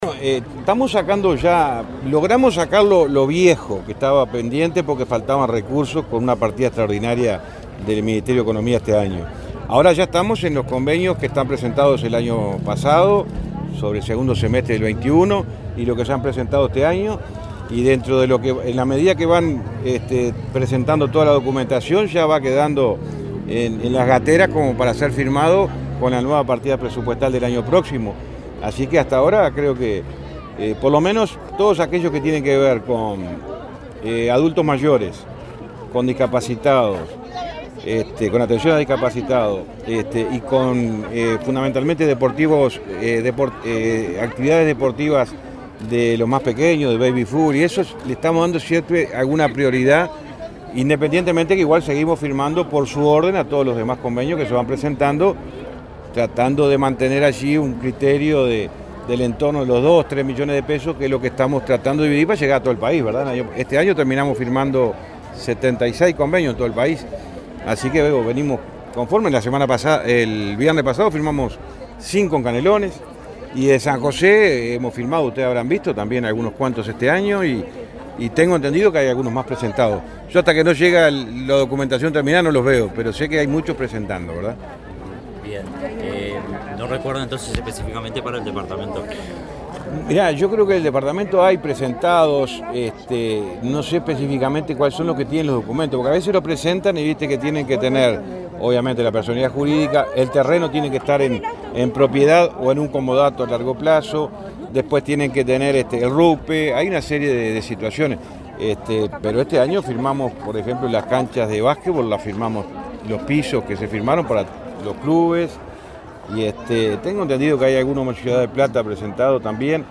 Declaraciones de ministro de Transporte, José Luis Falero
Luego dialogó con la prensa.